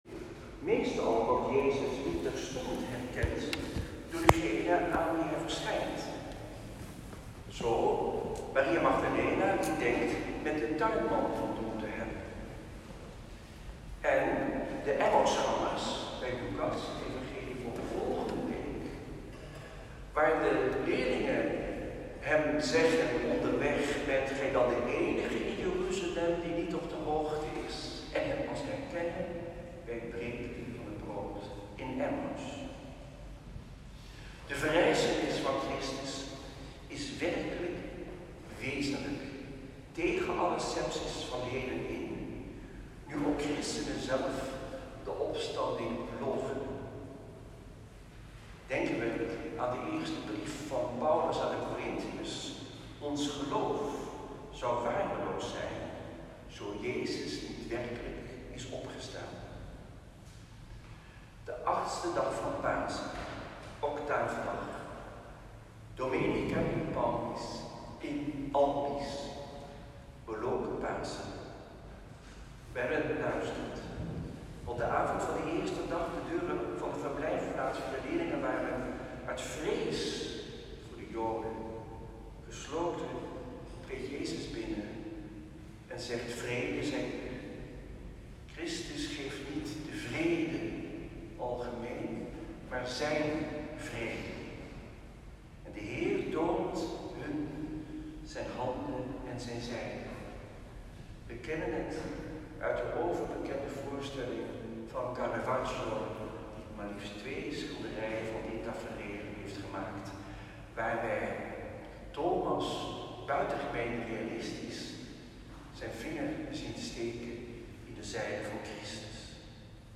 Tweede zondag van Pasen. Celebrant Antoine Bodar.
Preek
Preek-1.m4a